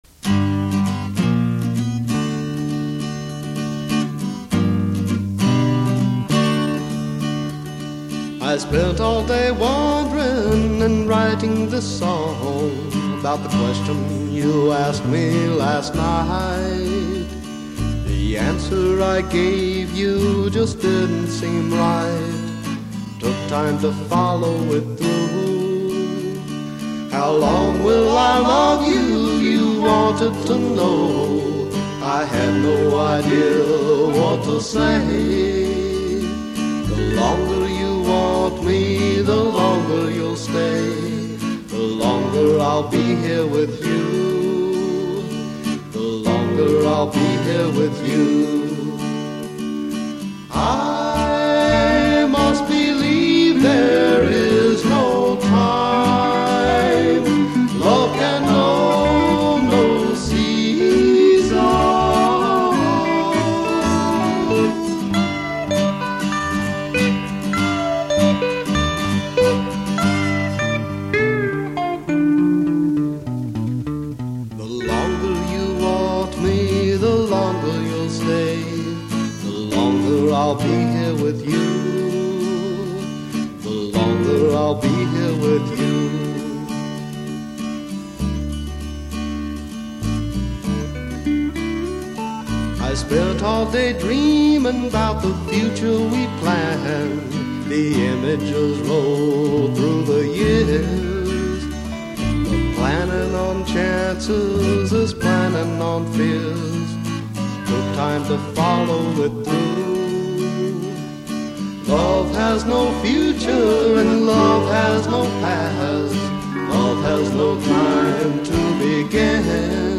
featuring me on acoustic, electric, bass, and vocals.